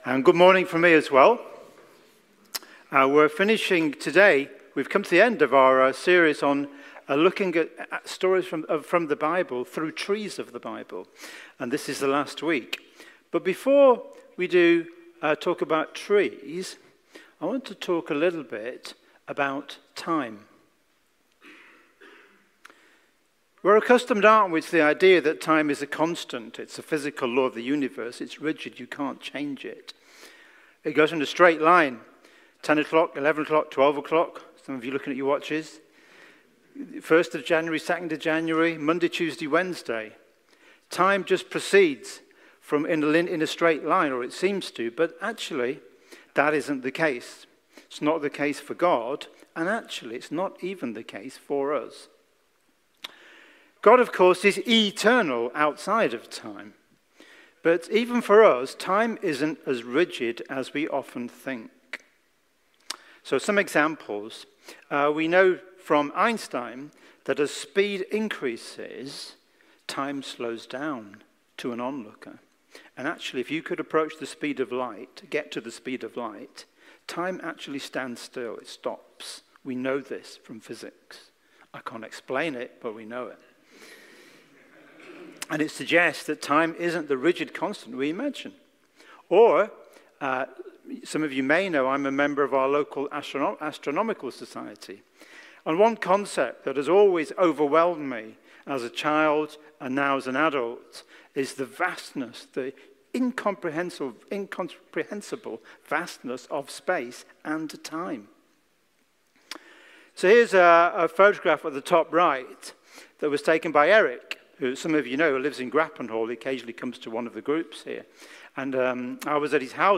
Trees of the Bible Passage: Revelation 21:1-8, Revelation 22:1–5 Service Type: Sunday Morning